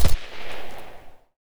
MachinegunShot.wav